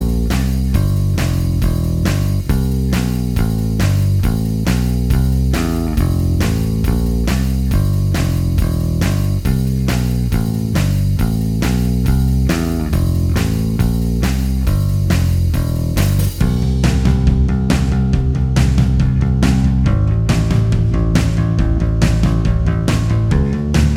Minus Guitars Pop (1980s) 3:15 Buy £1.50